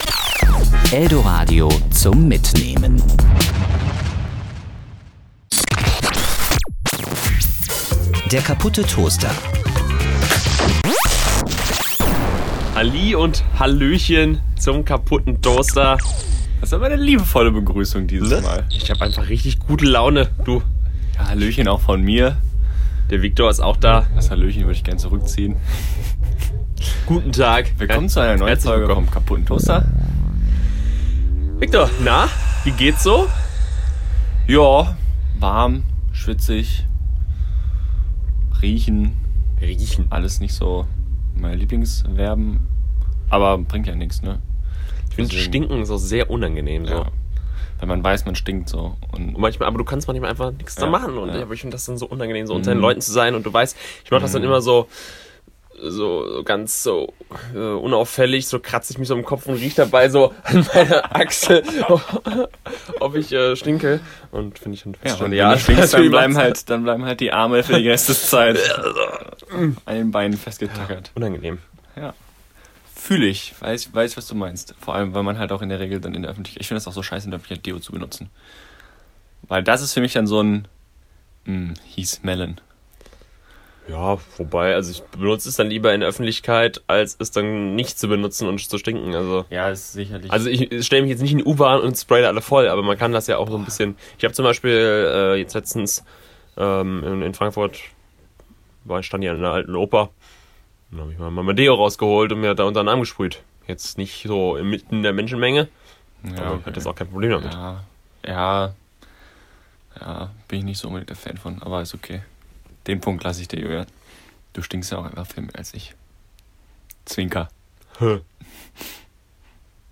Außerdem wird herzlich über Milch und Comedy gelacht.